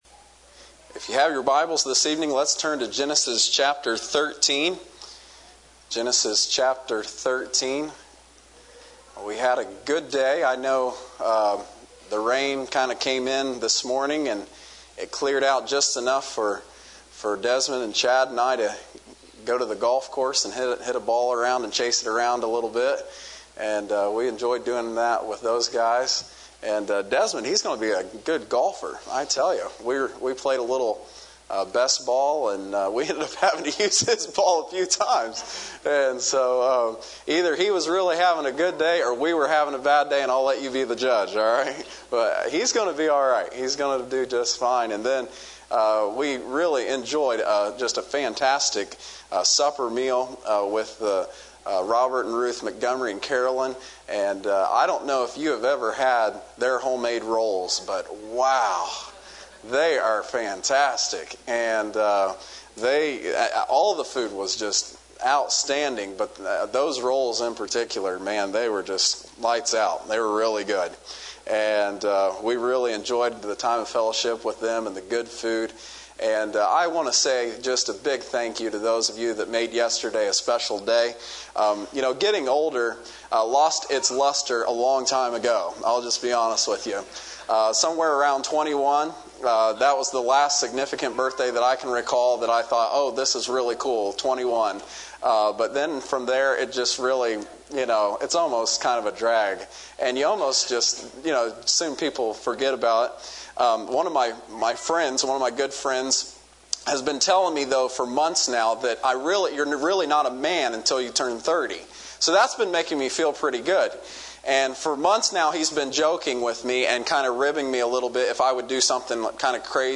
Series: Youth Revival 2016